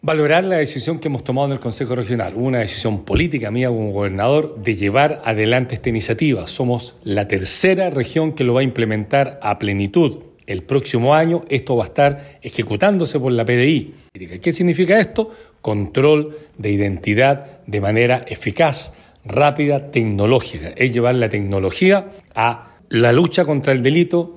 En este mismo sentido, el Gobernador Patricio Vallespín, valoró la aprobación del sistema y que Los Lagos sea una de las primeras regiones en implementarlo.
cuna-biometricosiii-gobernador.mp3